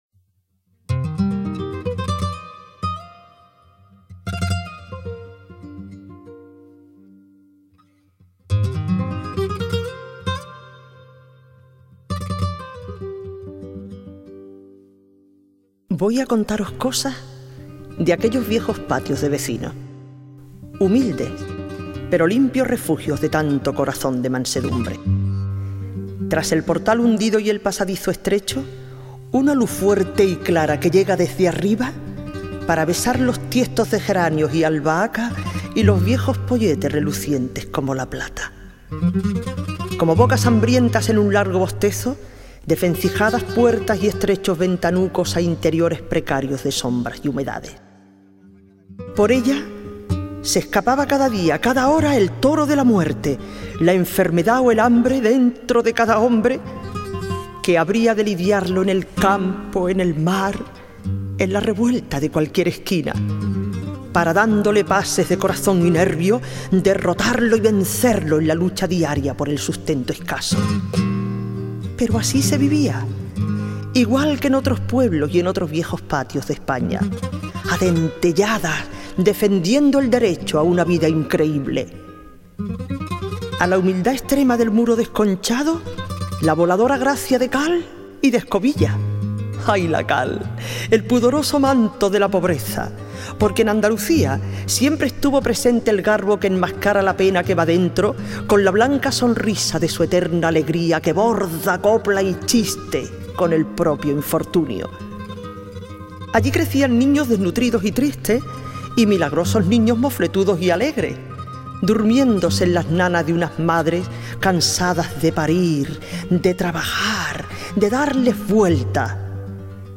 Poemas